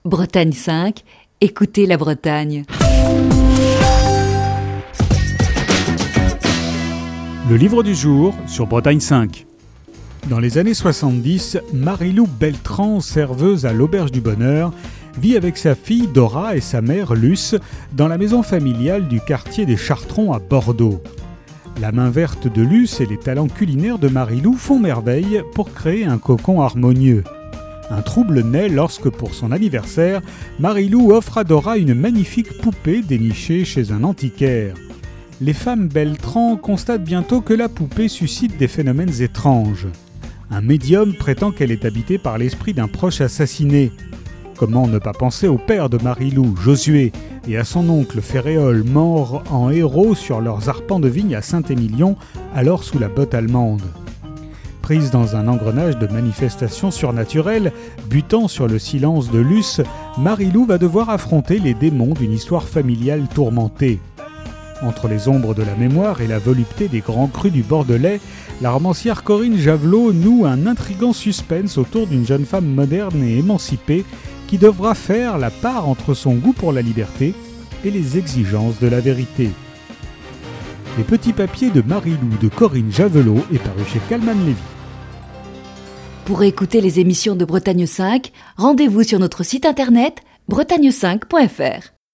Chronique du 5 mars 2021.